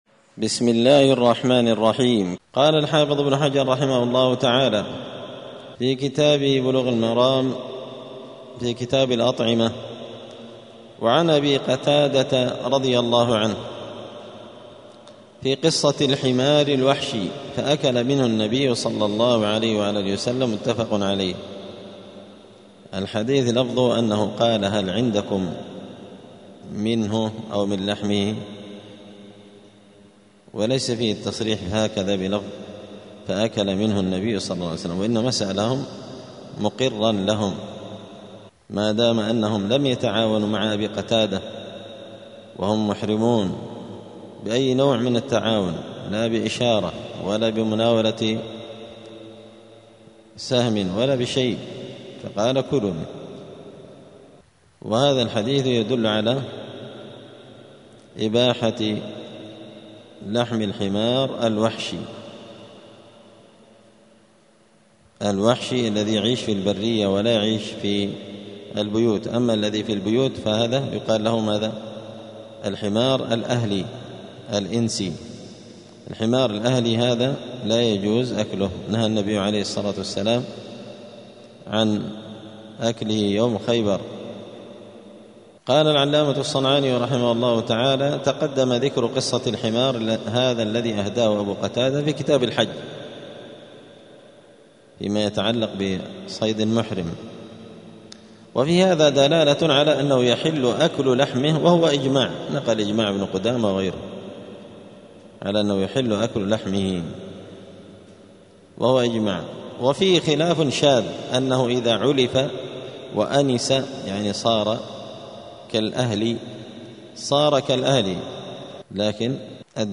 *الدرس السادس (6) {حكم أكل الحمار الوحشي}*
دار الحديث السلفية بمسجد الفرقان قشن المهرة اليمن